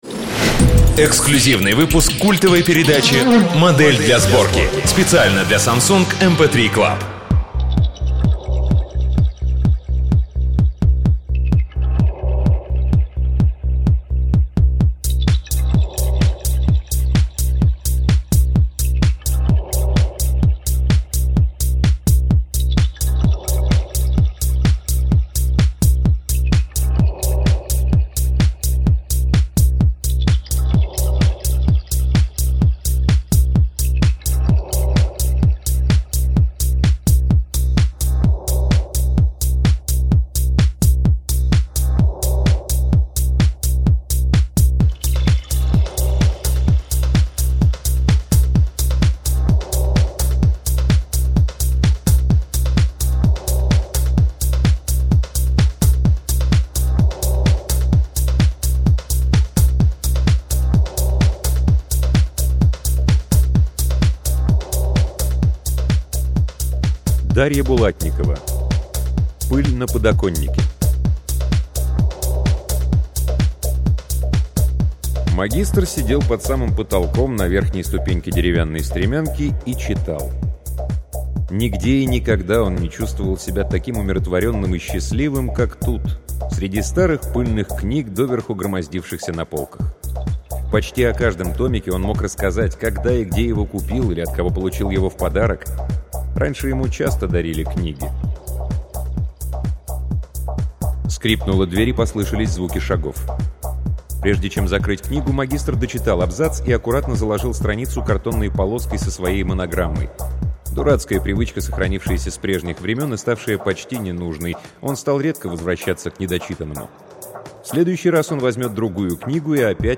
Аудиокнига Дарья Булатникова — Пыль на подоконнике